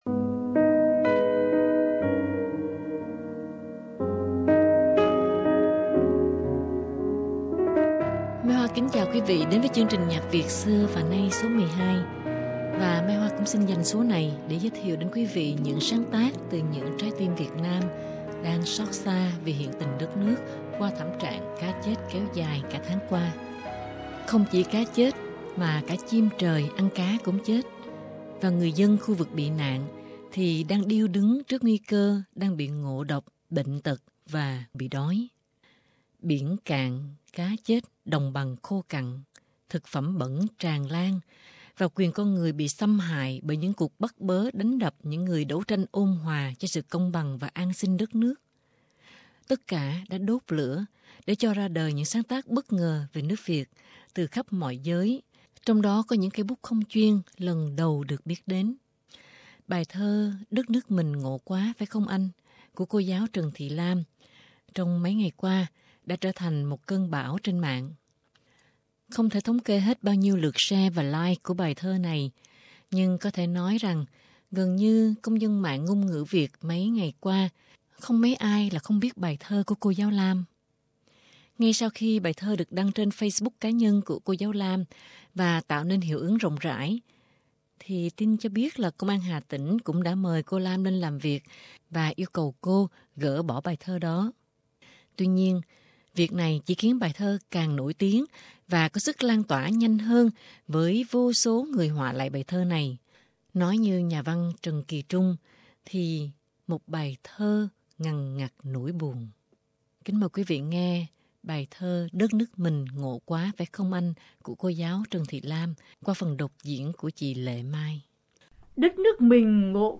các bài nhạc hip hop nhạc rap